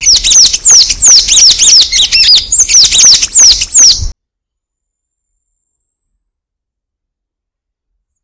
celeston.wav